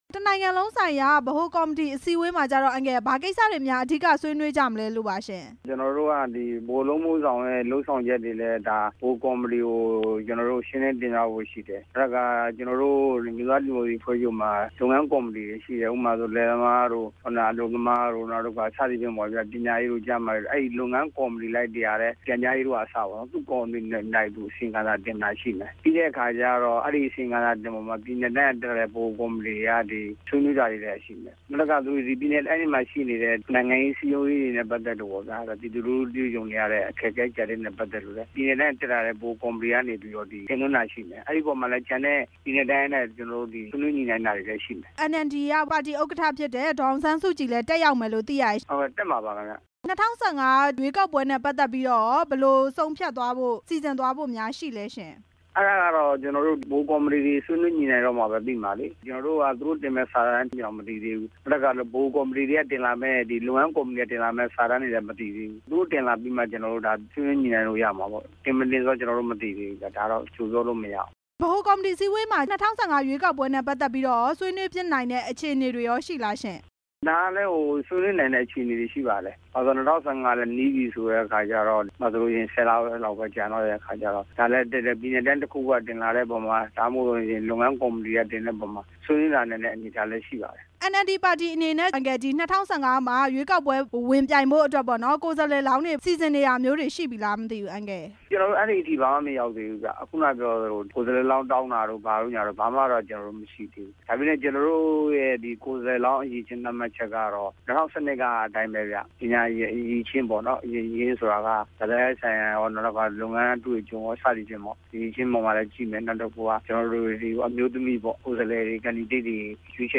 NLD နိုင်ငံလုံးဆိုင်ရာ ဗဟိုကော်မတီ အစည်းအဝေးအကြောင်း ဦးဝင်းမြင့်နဲ့ မေးမြန်းချက်